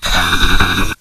mobs_pig_angry.ogg